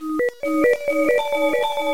8位视频游戏的声音 " 下降的回声 - 声音 - 淘声网 - 免费音效素材资源|视频游戏配乐下载
粗糙的正弦波形状随着回声而在音调中下降。通过单击随机按钮使用Chiptone进行处理。